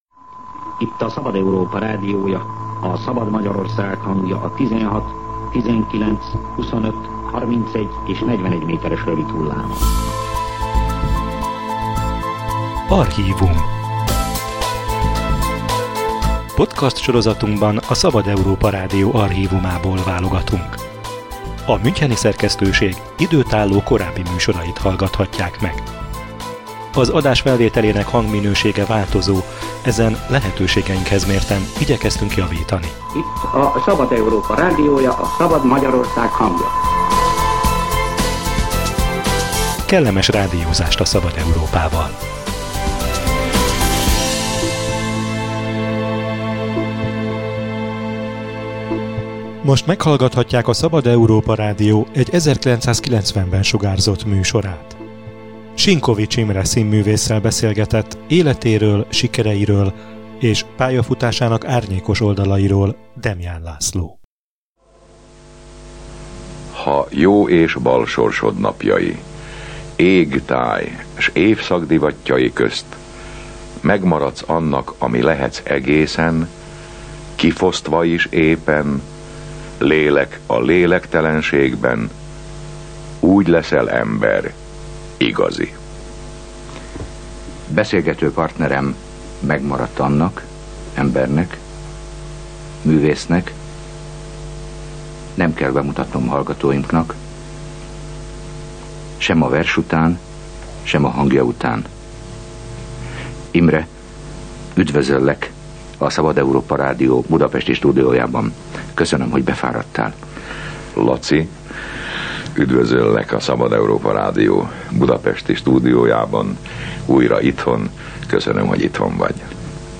A forradalom napjaiban aktívan részt vett a művészvilág szervezésében, később csak a népszerűsége mentette meg a börtöntől. Sinkovits Imrét 1990-ben kérte visszaemlékező beszélgetésre a Szabad Európa Rádió.